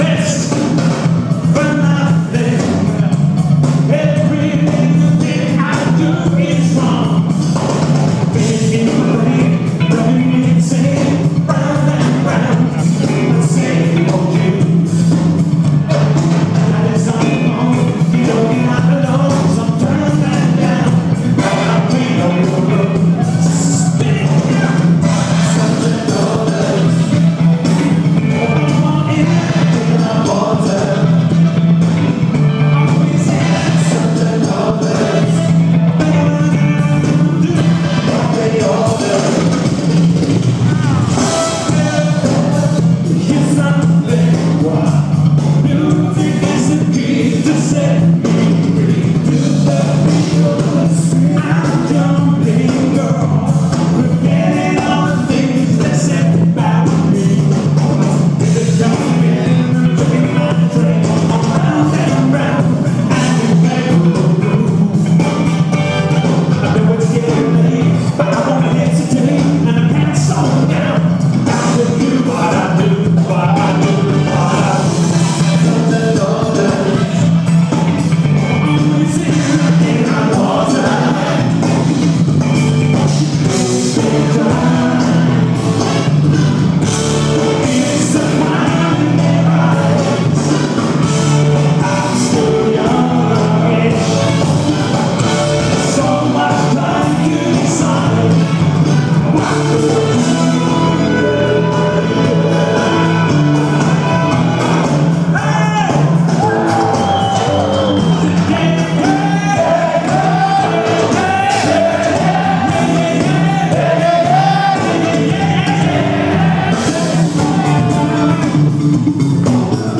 Hot water from last mights gig